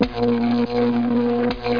06130_Sound_Shock.mp3